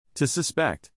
a suspect /SU-spect/ (someone who the police believe may have committed a crime) vs